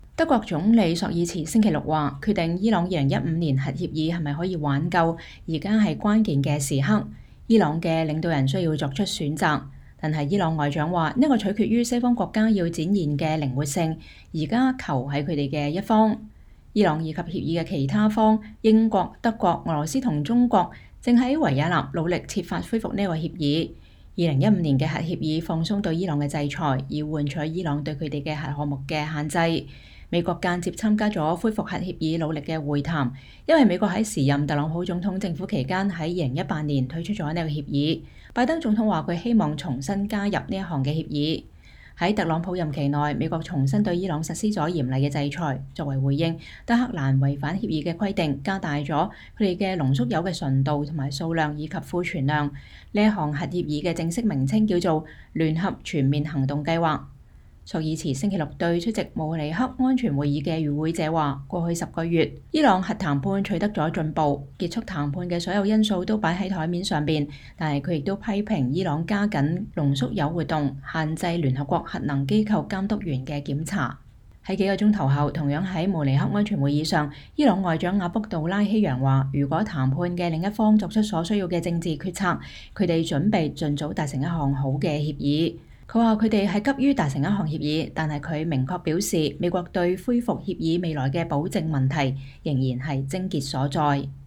德國總理朔爾茨2022年2月19日在每年一度的慕尼黑安全會議上演講。